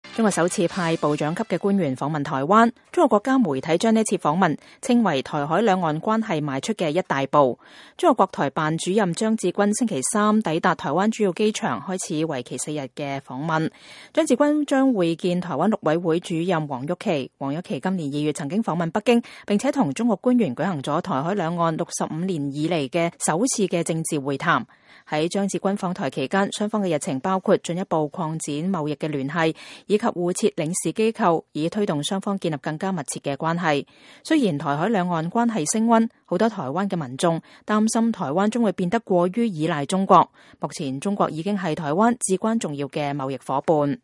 2014-06-25 美國之音視頻新聞: 中國國台辦主任張志軍抵達台灣